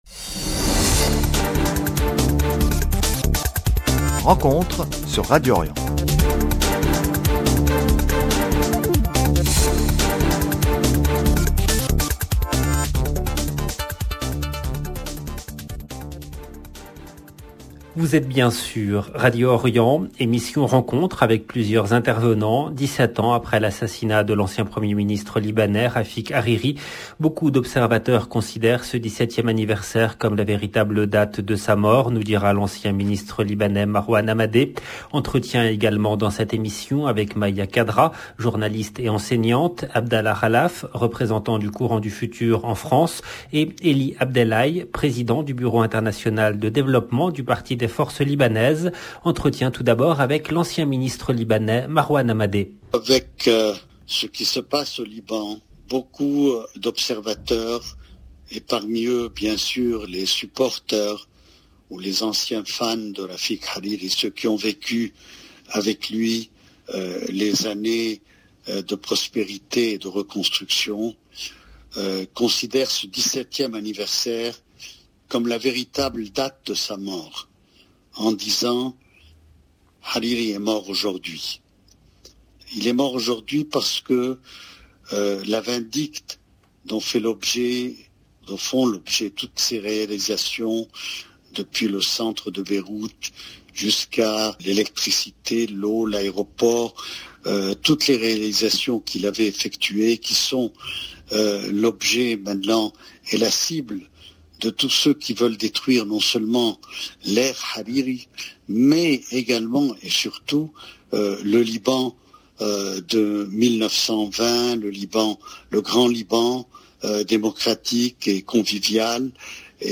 Rafic Hariri Liban 14 février 2005 Attentat 14 février 2022 - 18 min 42 sec Hommage : 17e anniversaire de l'assassinat de Rafic Hariri LB RENCONTRE, lundi 14 février 2022 Emission Rencontre avec plusieurs intervenants 17 ans après l’assassinat de l’ancien Premier ministre libanais Rafic Hariri. Beaucoup d’observateurs considèrent ce 17eme anniversaire comme la véritable date de sa mort nous dira l’ancien ministre libanais Marwan Hamadé.